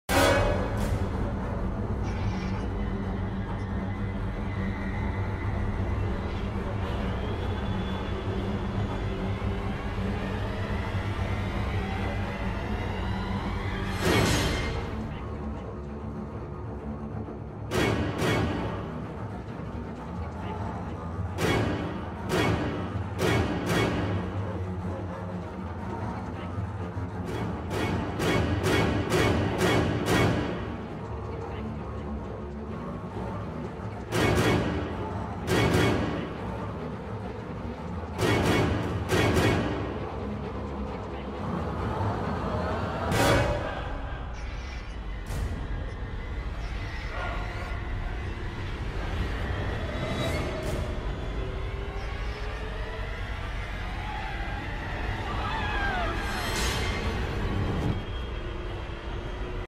(GAMING) Soundtrack theme